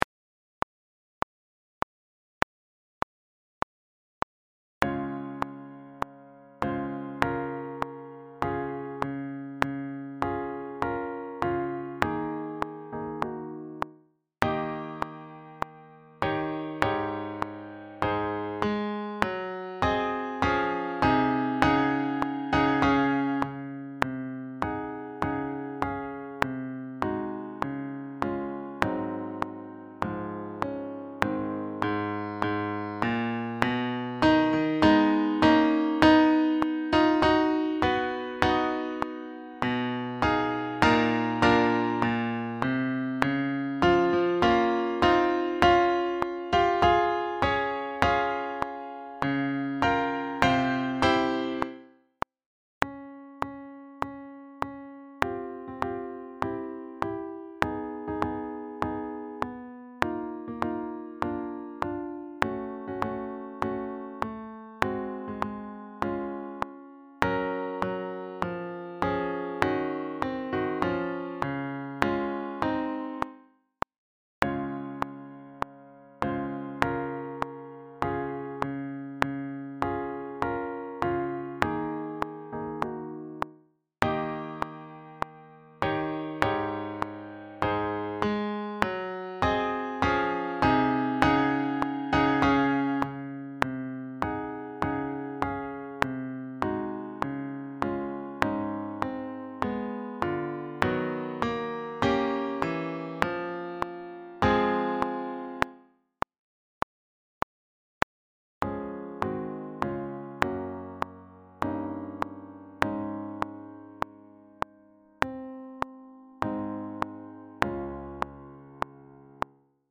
Sax Quartets
Backing track